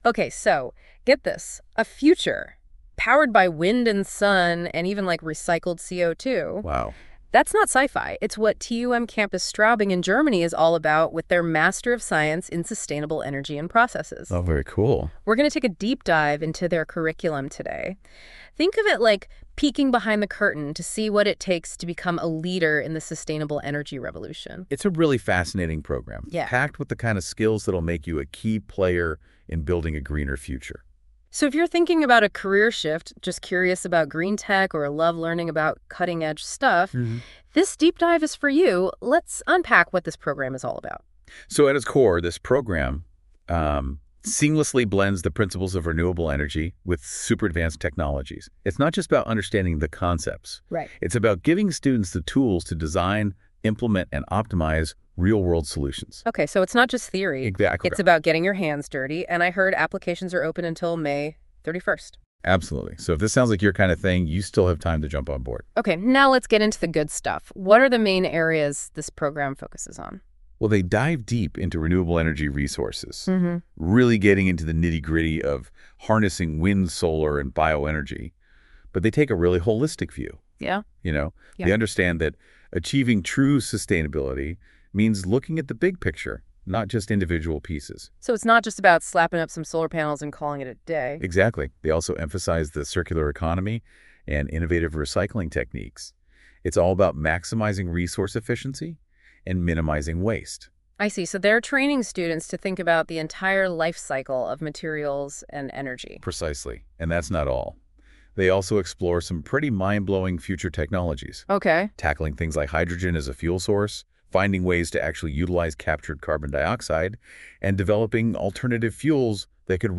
Interview to the TUM Master Sustainable Energy and Processes at TUMCS in English
Master__Sustainable_Energy_and_Processes_Interview.mp3